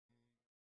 وفات حضرت معصومه (س) شور -( گواه ِ درد ِ غریبی ات ، چشم ِ تر ِ زهرا و علی )